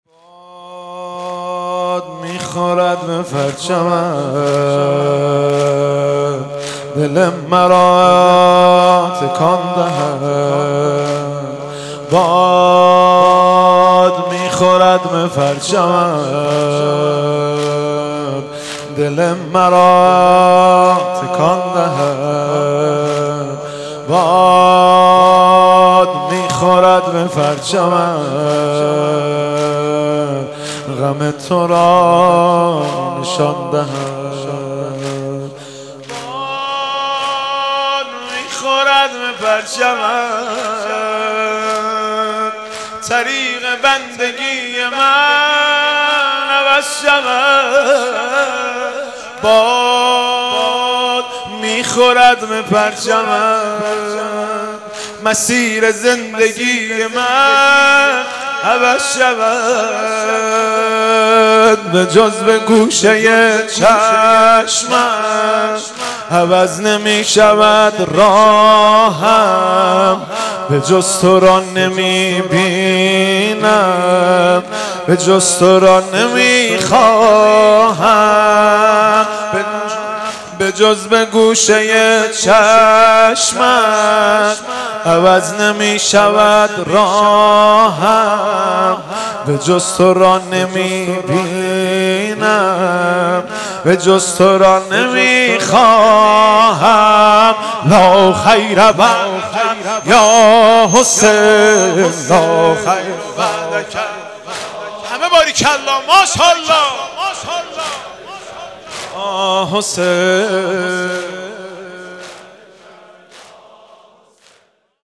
پیش زمینه باد میخورد به پرچمت با صدای حاج مهدی رسولی در شب اول محرم الحرام ۱۴۴۲
پیش زمینه باد میخورد به پرچمت با صدای حاج مهدی رسولی در شب اول محرم الحرام ۱۴۴۲ (مصادف با پنجشنبه سی ام مرداد ماه ۱۳۹۹ ش) در هیئت ثاراللّه (رهروان امام و شهدا)